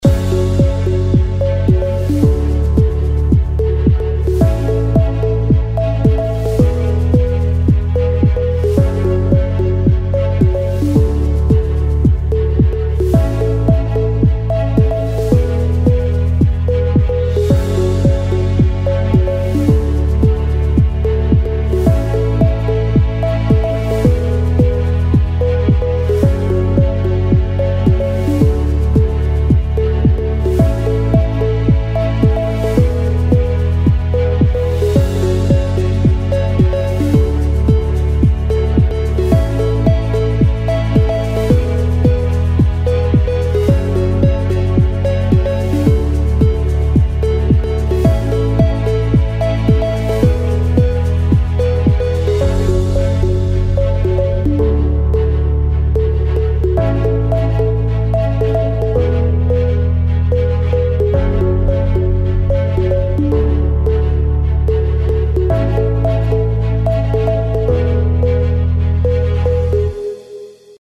دانلود زنگ موبایل ملایم برای صدای زنگ گوشی